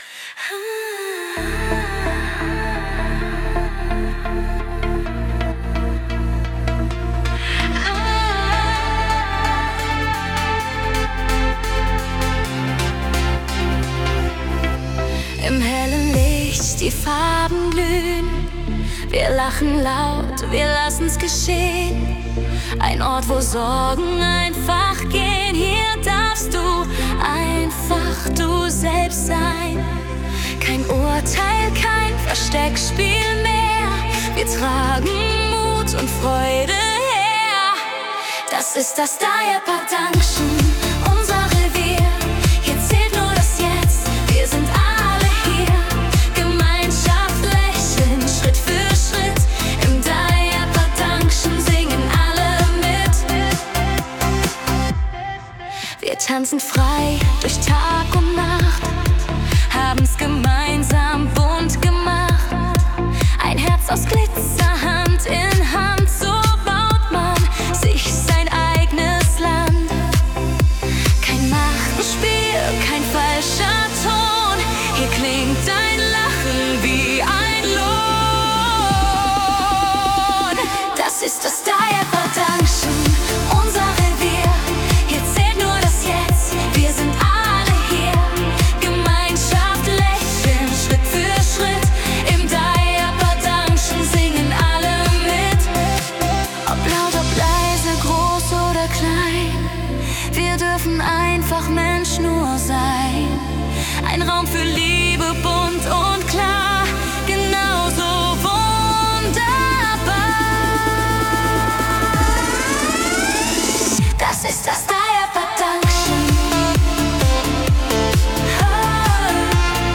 ein fröhlicher, befreiender Song
Genre: Pop/Elektro